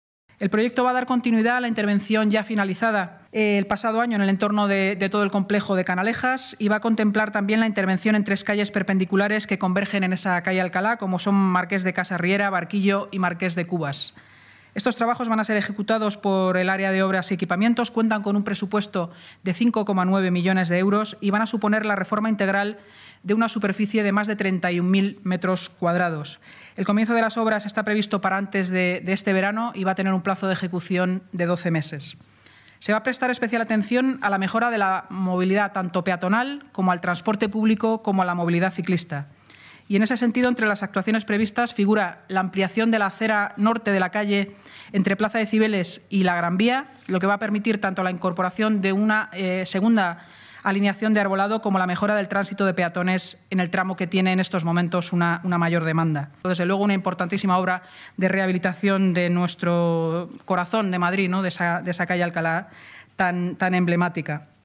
El Ayuntamiento de Madrid ha aprobado hoy el proyecto para la remodelación de la calle Alcalá en el tramo comprendido entre la plaza de Cibeles y la calle Cedaceros, según ha explicado la portavoz municipal, Inmaculada Sanz, tras la reunión semanal de la Junta de Gobierno.